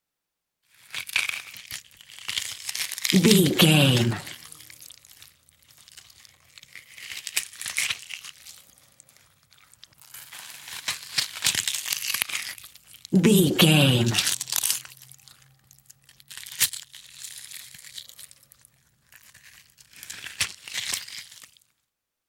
Creature eating rip tear flesh
Sound Effects
Atonal
scary
ominous
disturbing
eerie